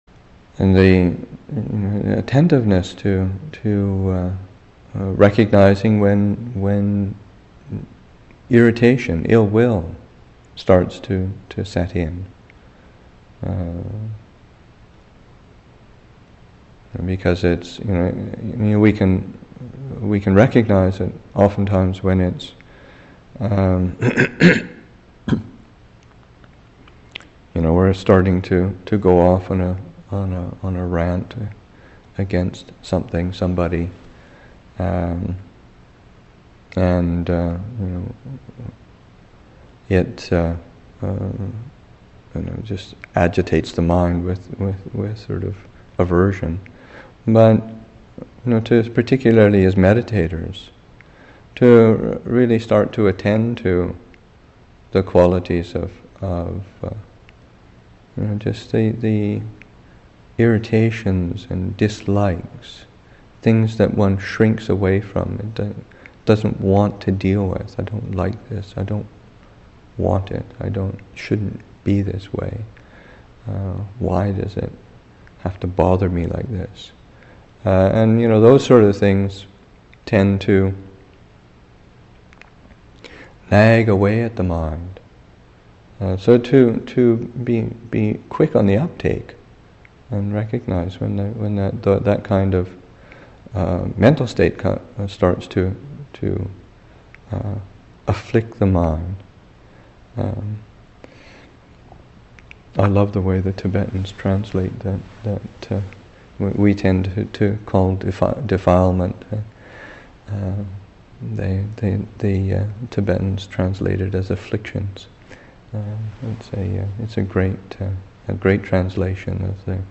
2. Recognizing subtle forms of irritation. Teaching